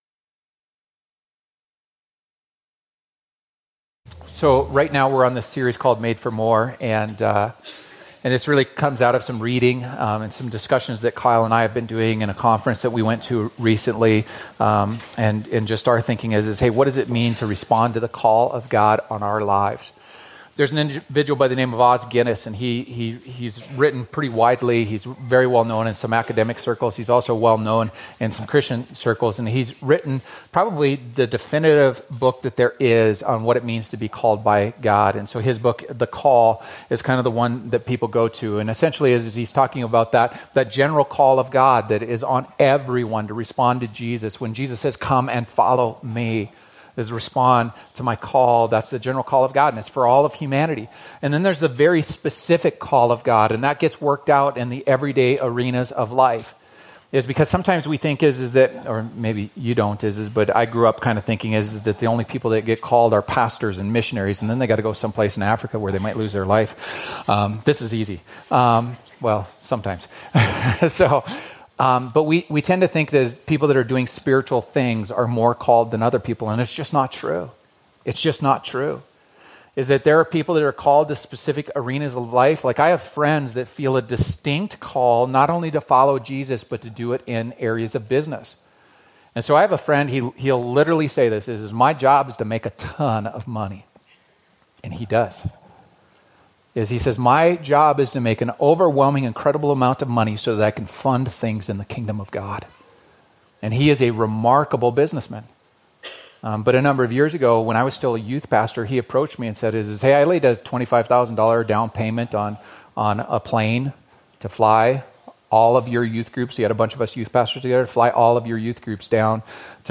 A message from the series "Made for More."